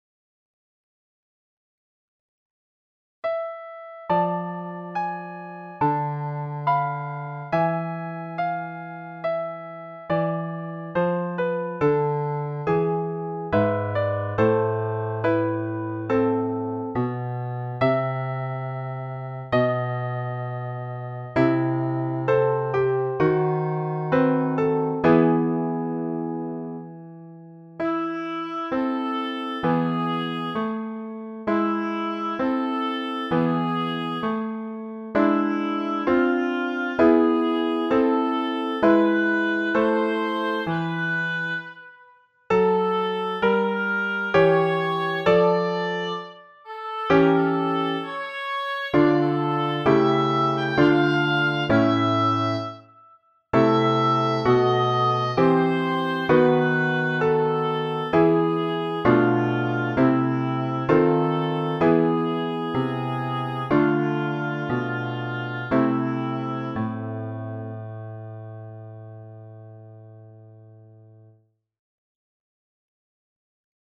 pour instruments et pianoforte